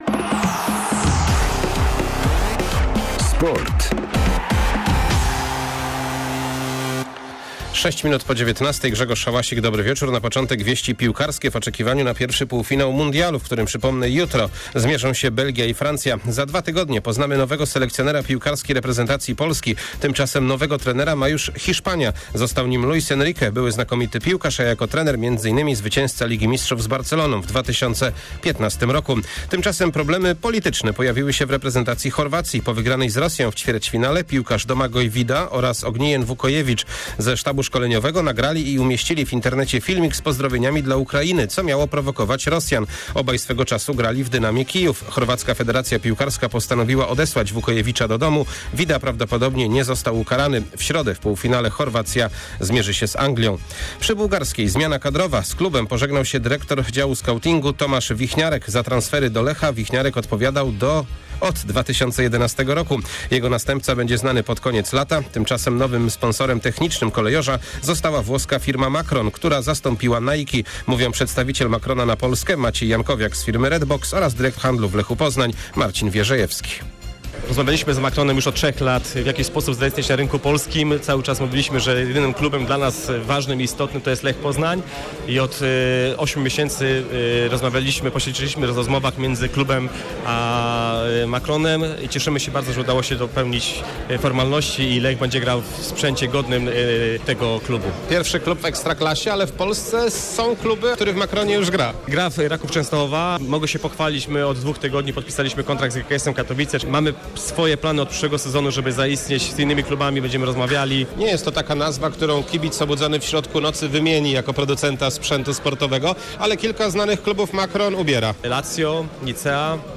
09.07 serwis sportowy godz. 19:05